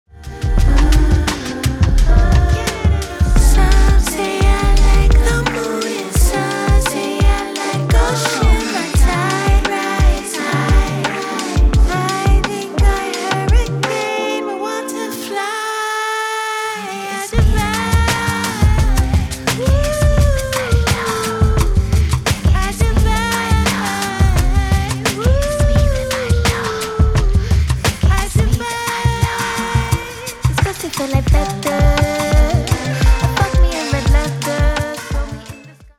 futuristic R’n’B